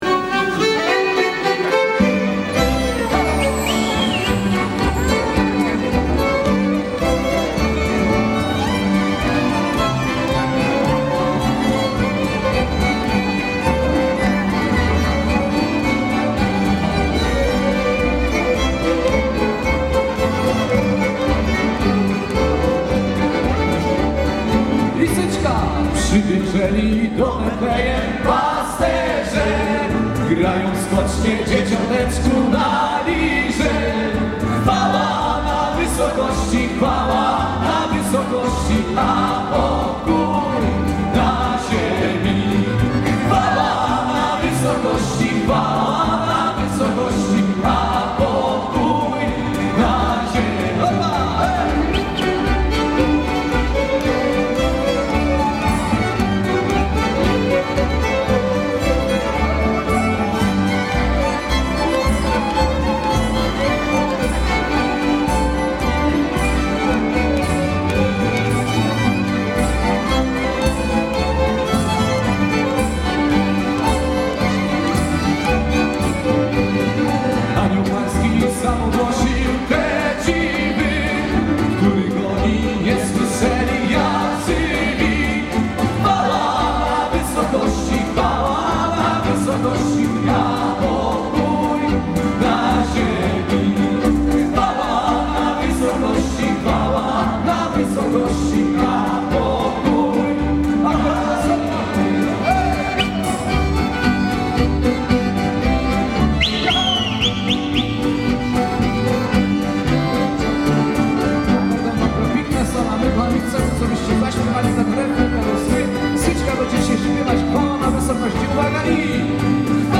Kościół był wypełniony po brzegi.
Świąteczny koncert w farze
Kościół farny wypełniony był po brzegi.